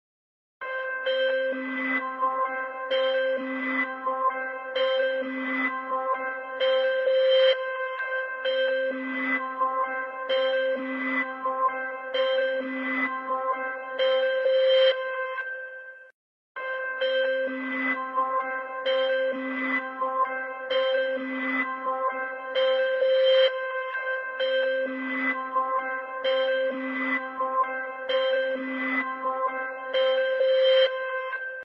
通知音 - Notification Tones